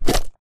slimeattack2.ogg